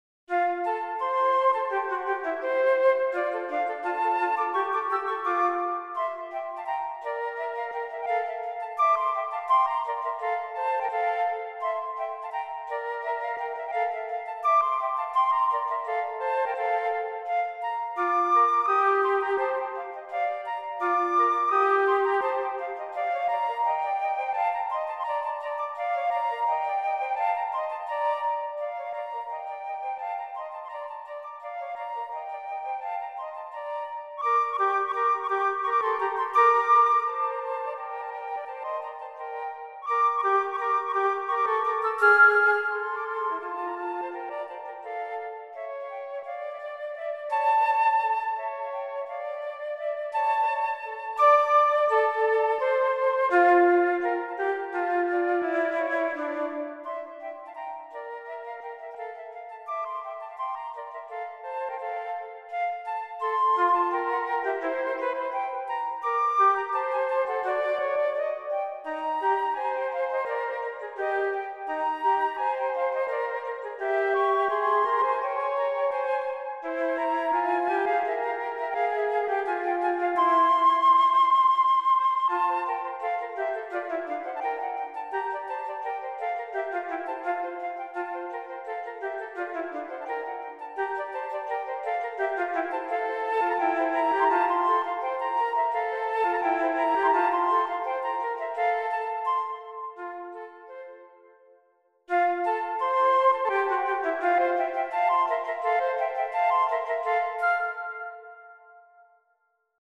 für 2 Flöten
Allegro marziale